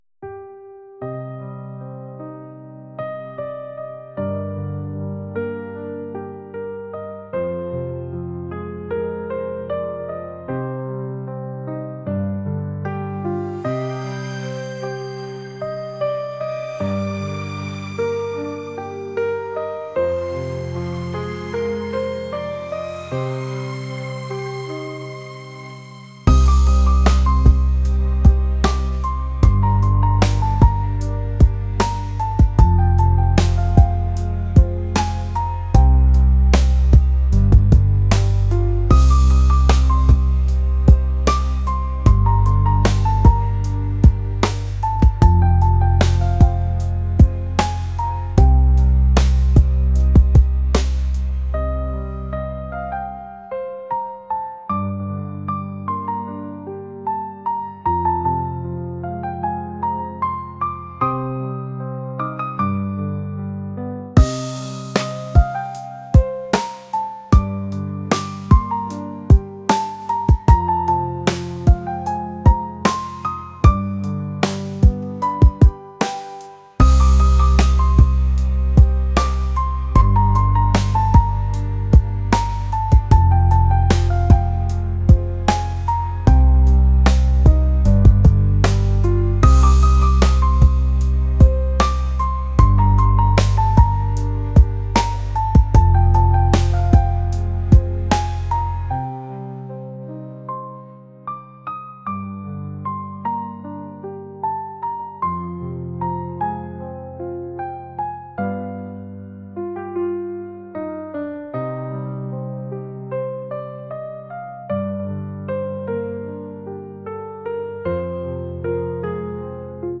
ambient | indie | pop